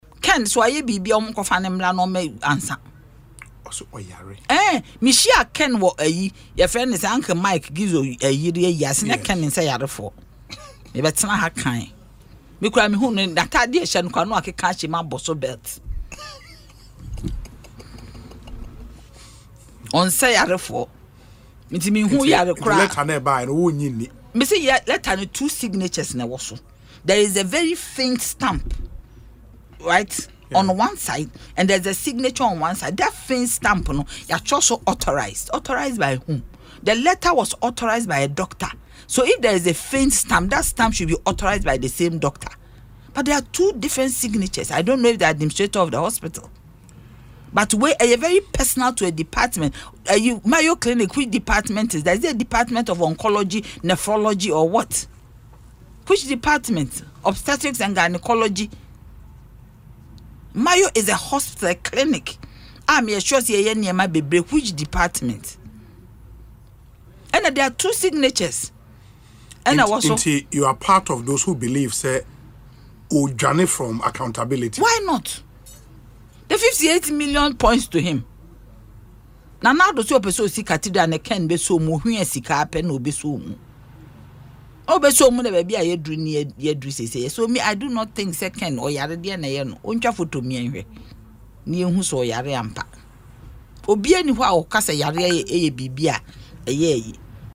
Speaking on Asempa FM’s Ekosii Sen on Wednesday, February 12, she questioned the authenticity of Mr. Ofori-Atta’s reported medical condition, downplaying the letter from his lawyers.